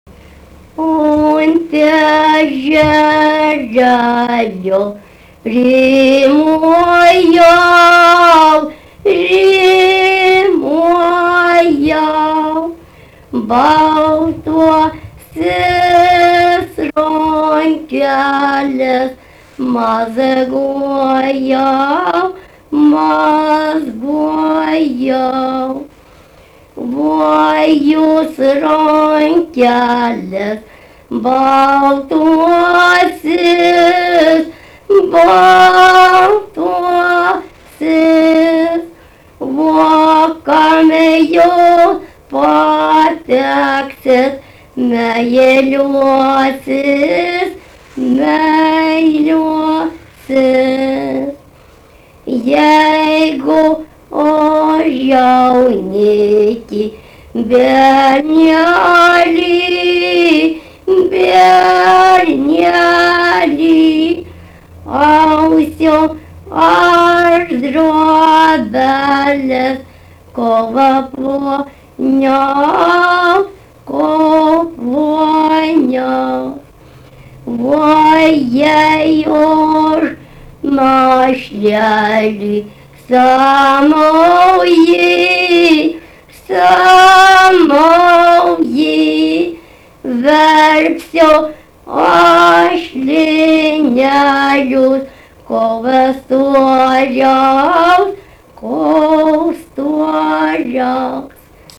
daina
Erdvinė aprėptis Daukšiai (Skuodas)
Atlikimo pubūdis vokalinis